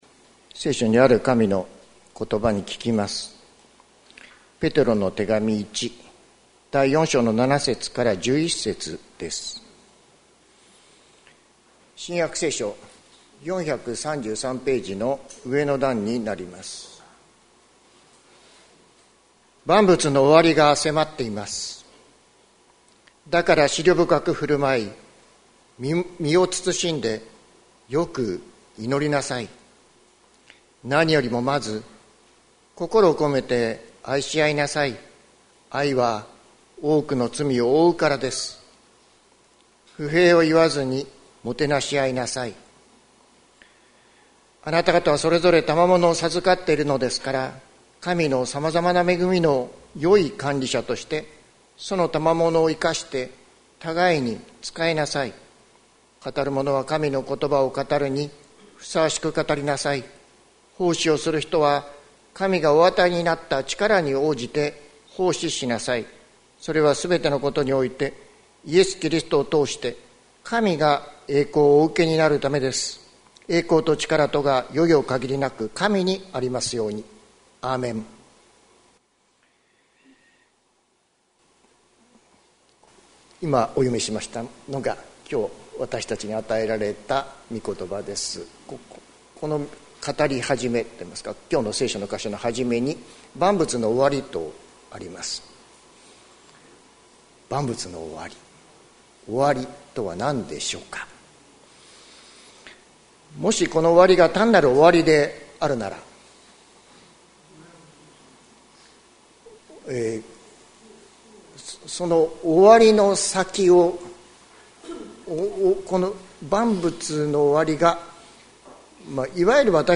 2025年02月02日朝の礼拝「愛は多くの罪を覆う」関キリスト教会
説教アーカイブ。